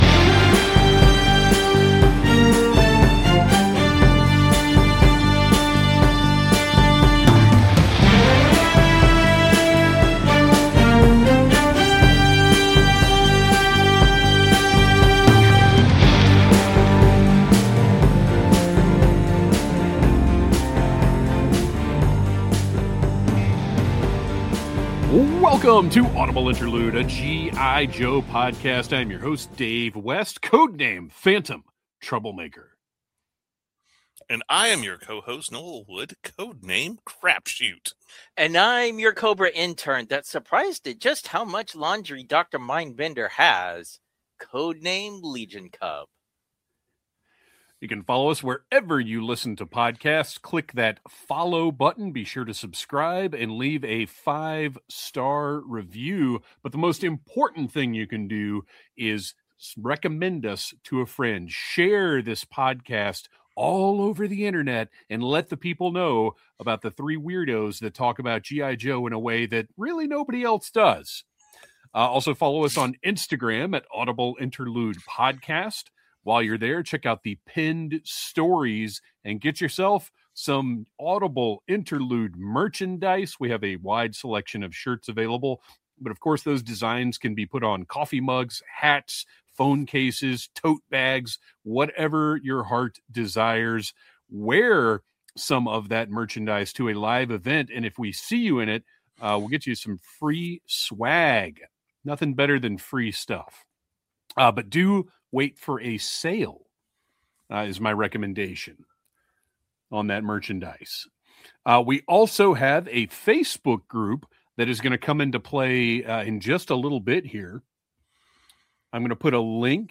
We're recruiting you to listen to this podcast hosted by three lifelong Joe fans.